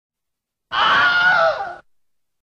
The Wilhelm scream sound effect.mp3
the-wilhelm-scream-sound-effect.mp3